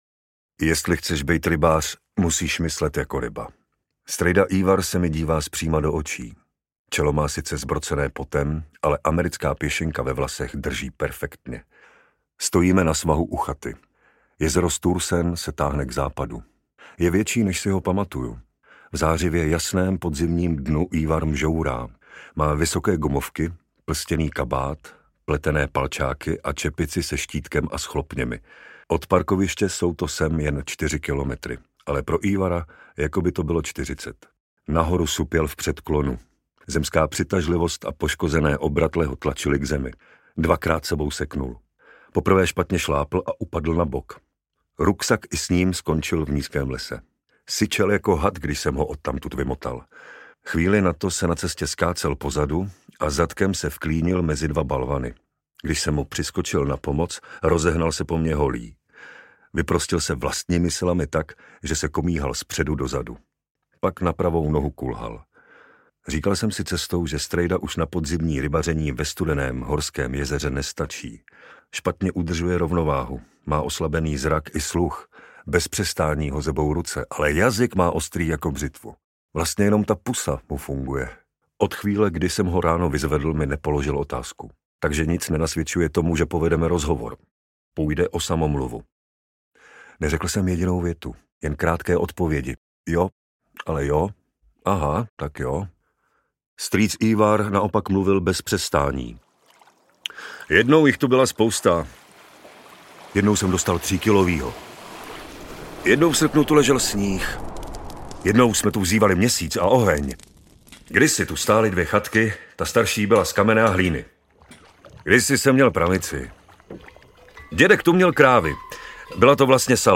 Rybářská chata audiokniha
Ukázka z knihy
Vyrobilo studio Soundguru.